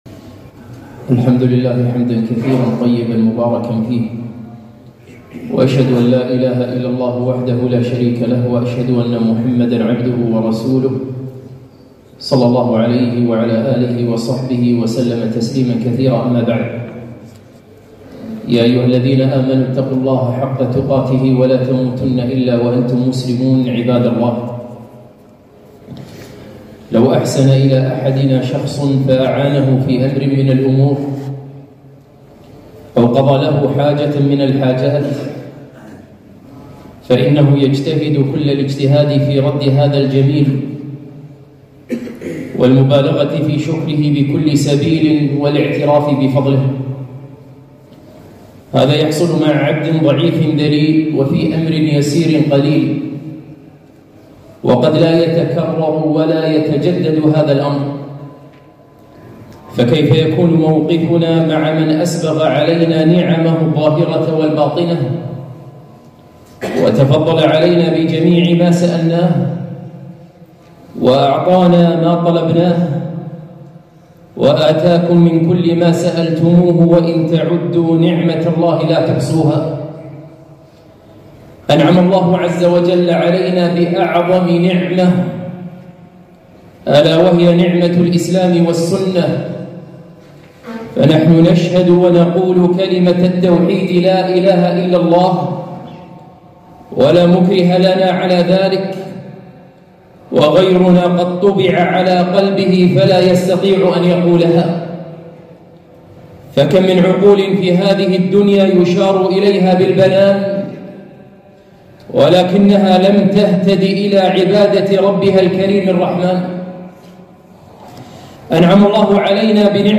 خطبة - شكر نعم الله